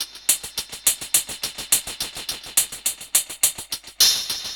Index of /musicradar/dub-drums-samples/105bpm
Db_DrumKitC_Wet_EchoHats_105-01.wav